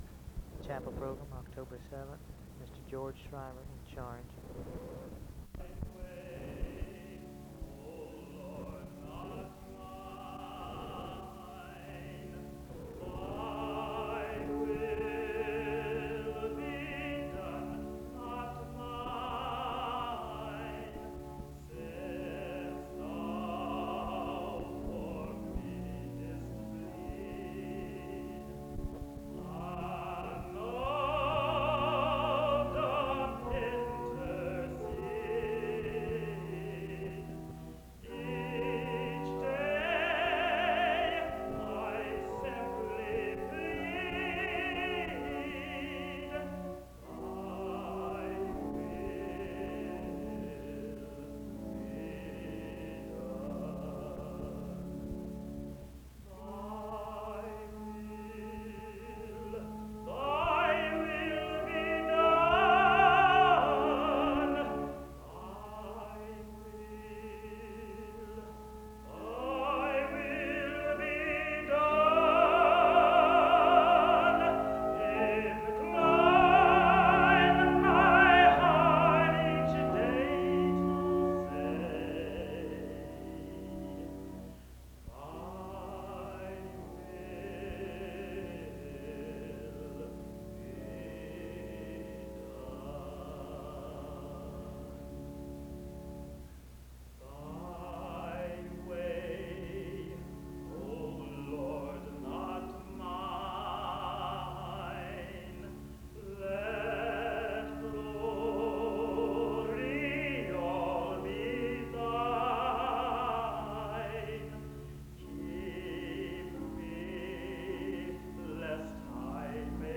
The service begins with music from 0:00-6:24.
A prayer is offered from 7:49-9:35.
Closing music and prayer takes place from 29:57-31:06.